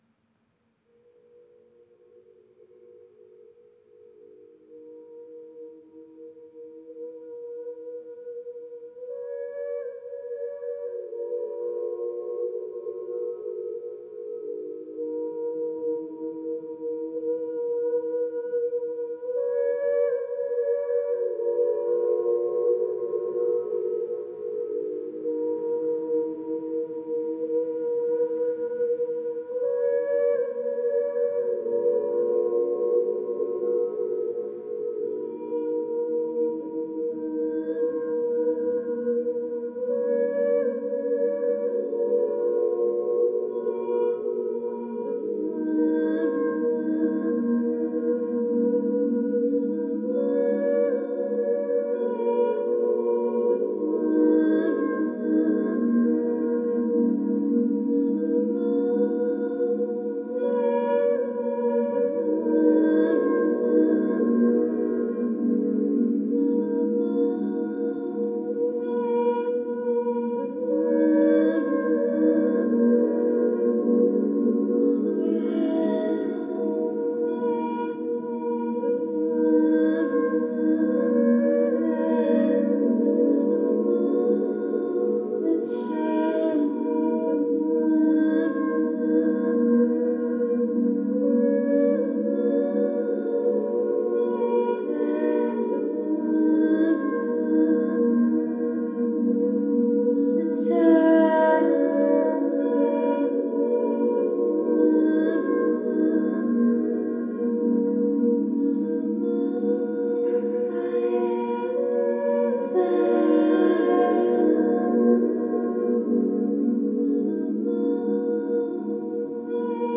Open studio time manifested as a couple of hours of really good conversation with a few bold blizzard navigators, punctuated by a brief bit of solo playing.